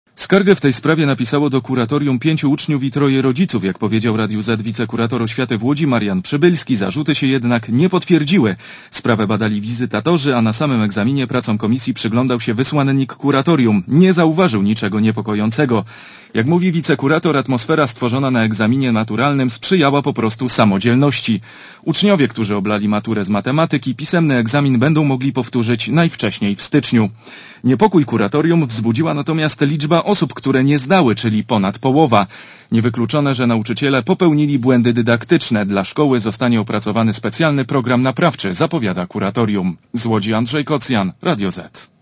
Relacja reportera Radia Zet (165Kb)